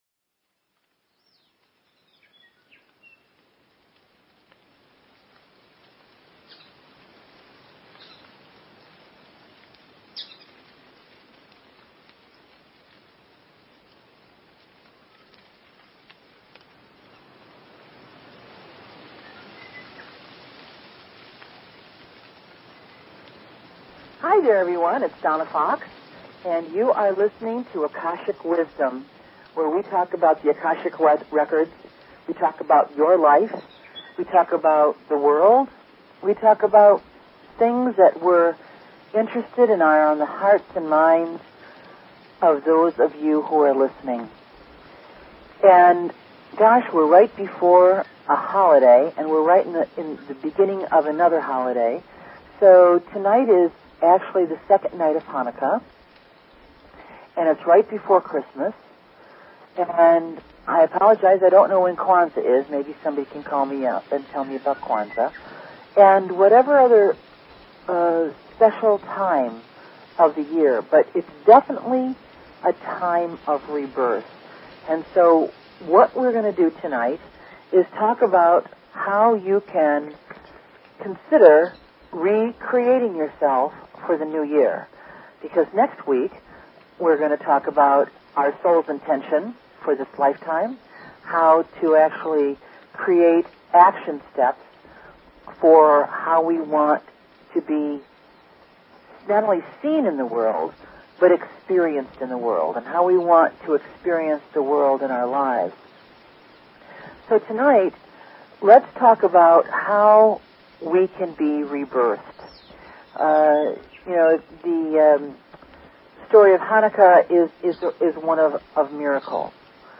Talk Show Episode, Audio Podcast, Akashic_Wisdom and Courtesy of BBS Radio on , show guests , about , categorized as
Call-Ins welcome!